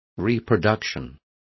Complete with pronunciation of the translation of reproductions.